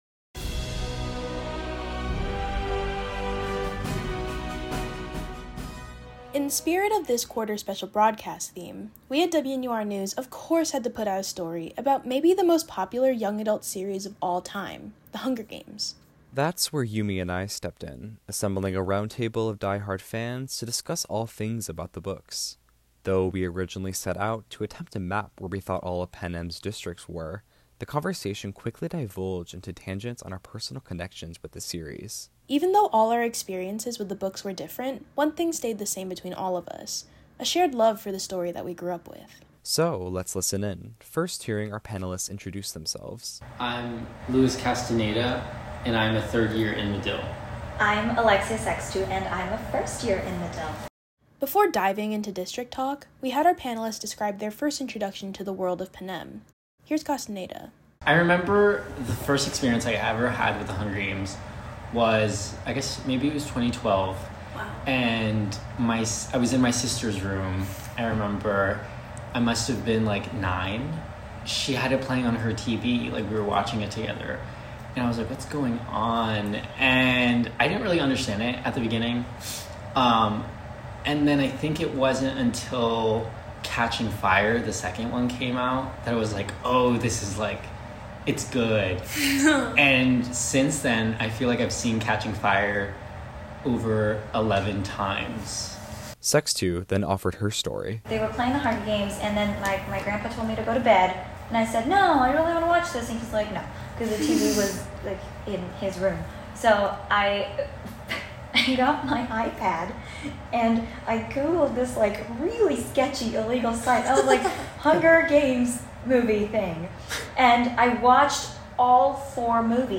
This originally aired as part of our Winter 2025 Special Broadcast: WNUR NEWS: Quarter Quell